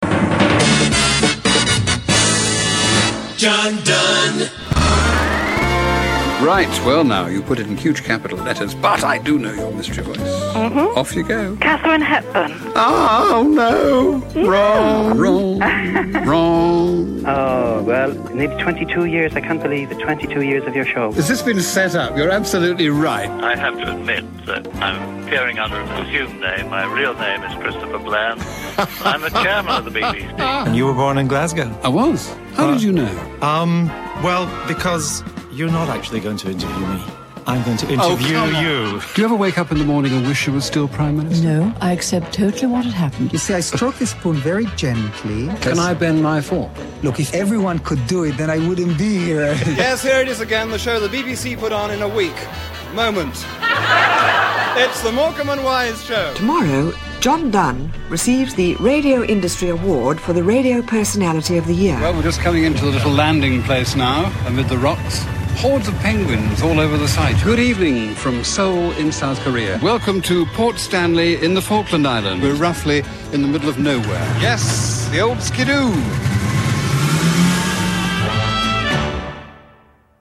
He combined smoothness of delivery with the hint of an impish smile and a healthy dose of charm and intelligence.